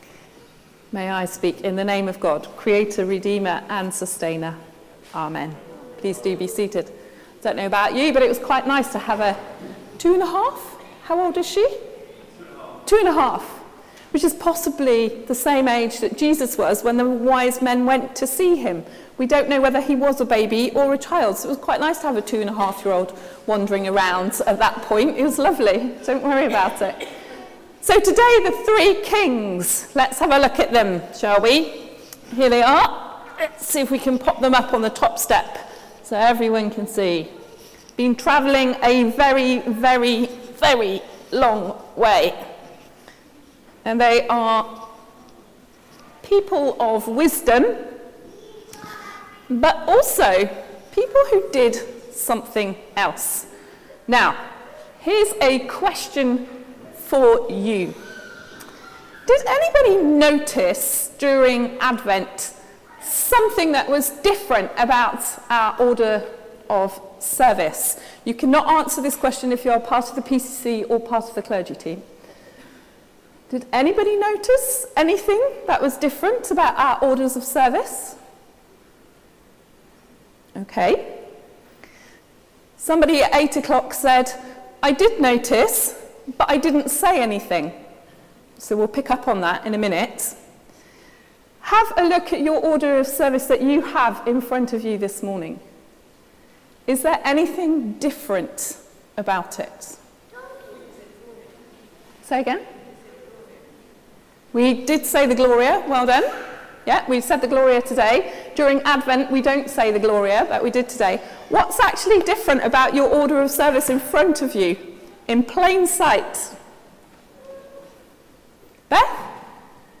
Sermon: Revelations in 2020 | St Paul + St Stephen Gloucester